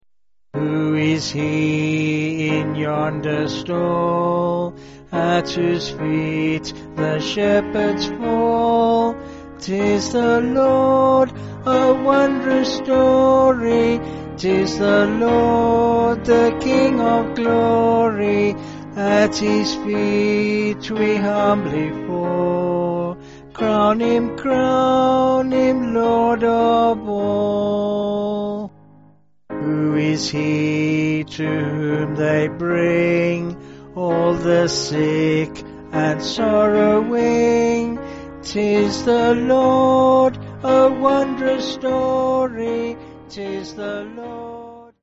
Vocals and Piano
8/Ab